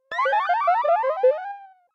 Life Lost Game Over
Category 🎮 Gaming
bleep die electronic game life lose lost over sound effect free sound royalty free Gaming